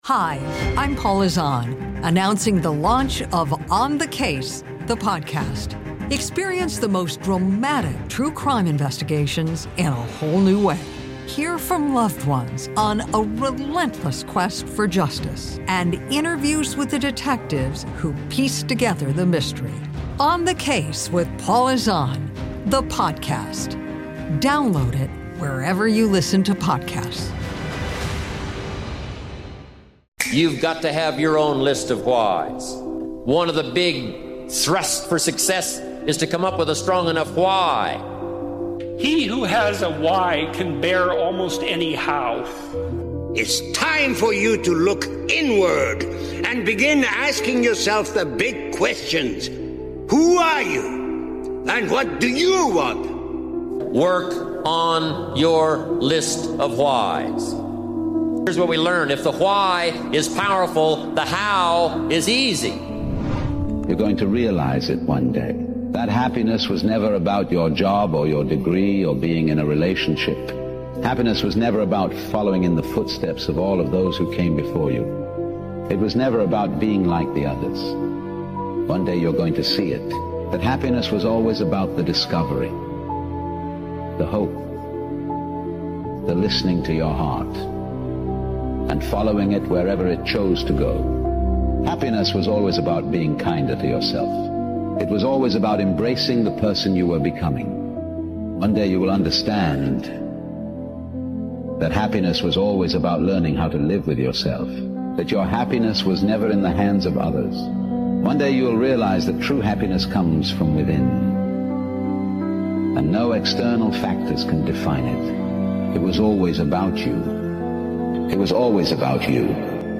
It’s not just another motivational speech — it’s a wake-up call. This message will remind you that a strong enough why bears any how.